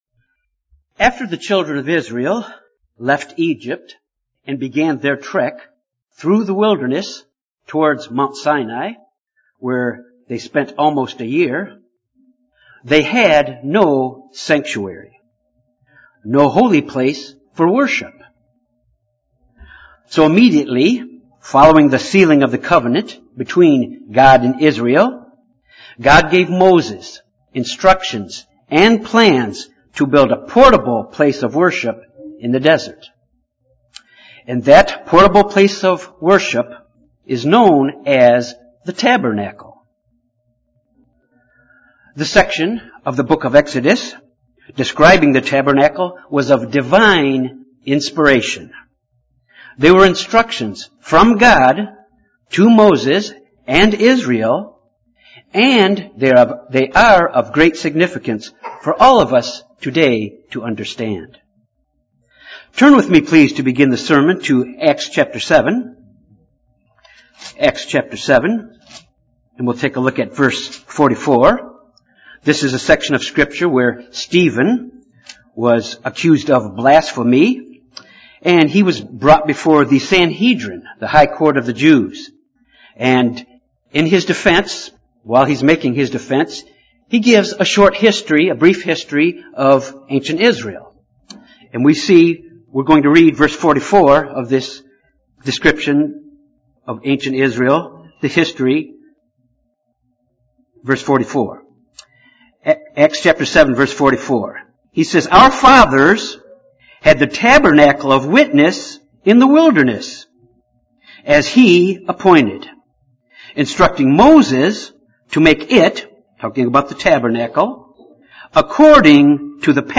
This sermon deals with the importance of the Tabernacle as it relates to us today.
Given in Little Rock, AR